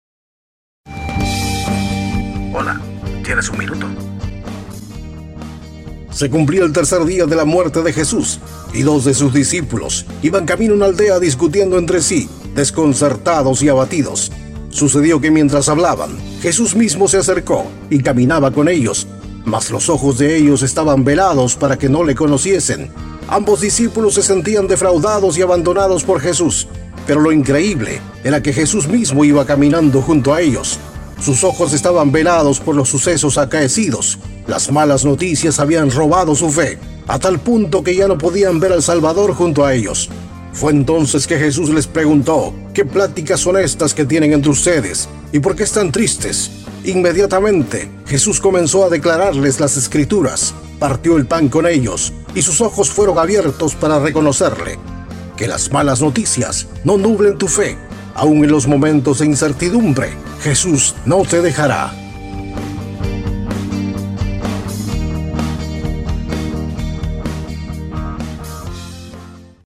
Programas radiales
Programa radial de 60 segundos, donde abordamos la vida de Jesús y sus palabras, aplicadas al mundo moderno.